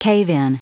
Prononciation du mot cave-in en anglais (fichier audio)
cave-in.wav